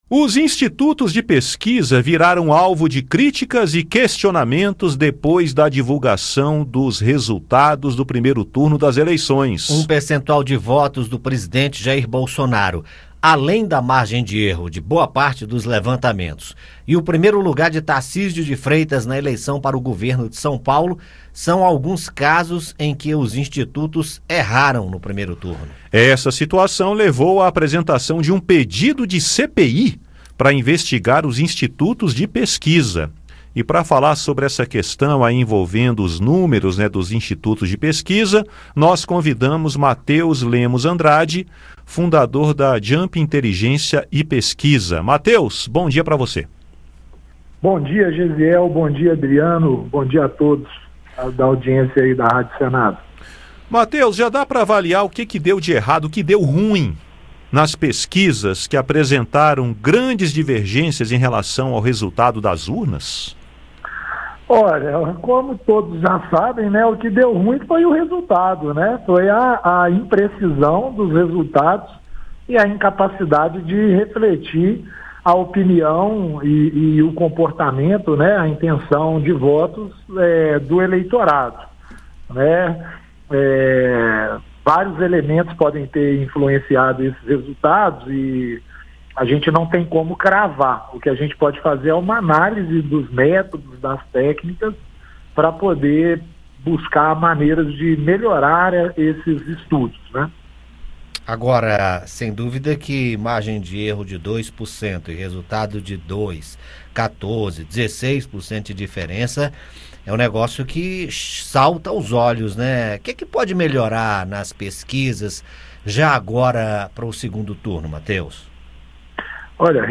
Em entrevista ao Conexão Senado